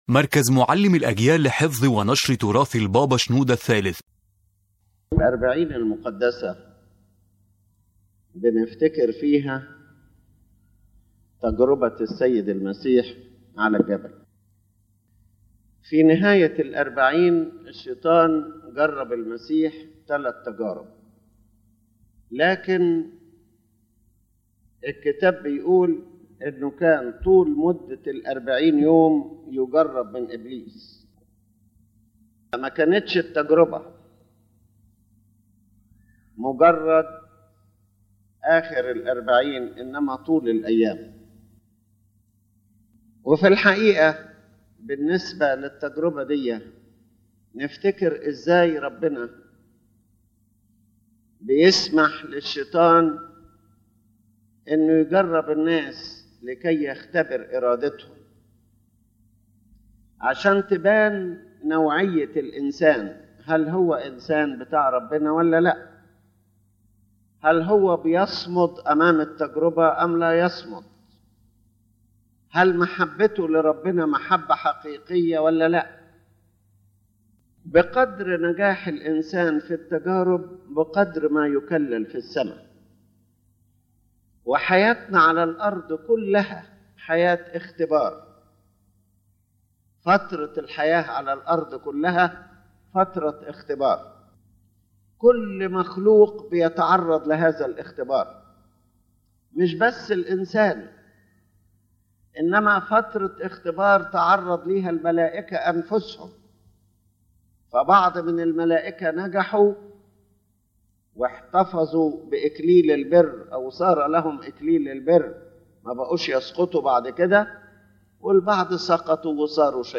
⬇ تحميل المحاضرة توضح المحاضرة أن حياة الإنسان على الأرض هي فترة اختبار مستمر لإرادته، حيث يسمح الله بالتجارب ليكشف حقيقة قلب الإنسان ومدى محبته وطاعته له.